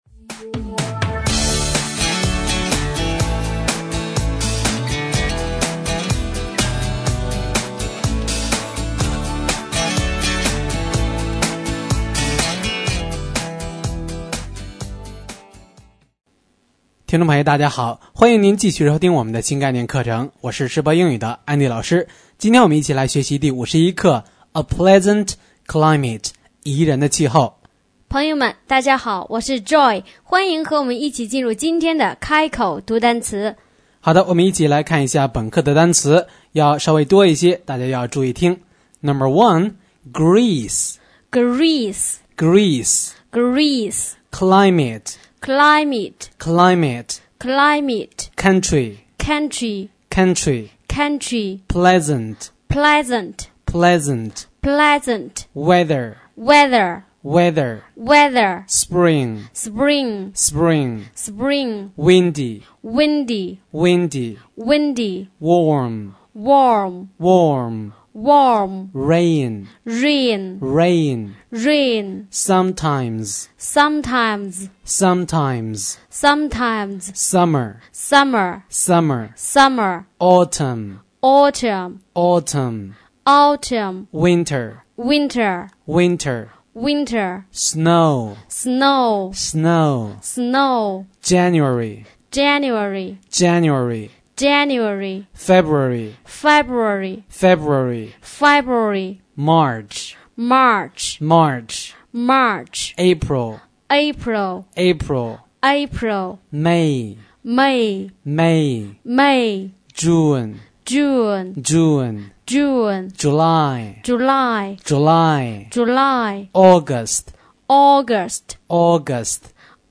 开口读单词